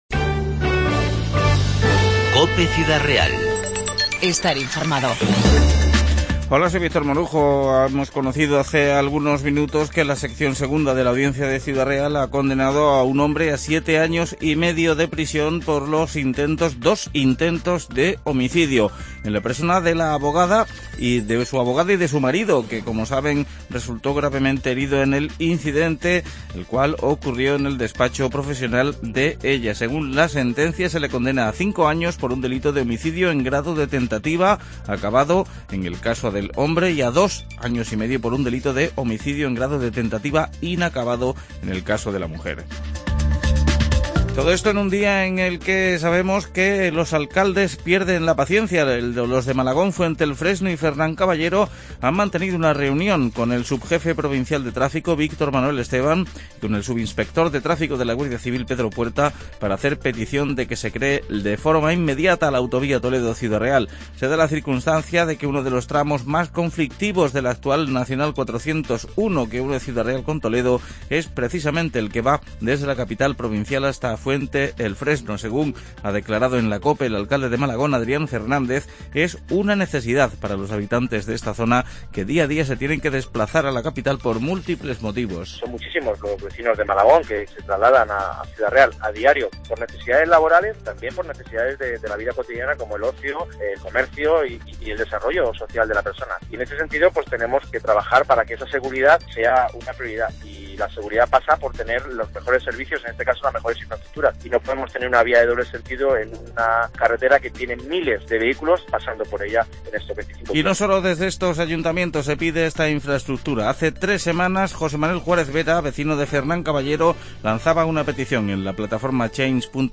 INFORMATIVO 20-10-15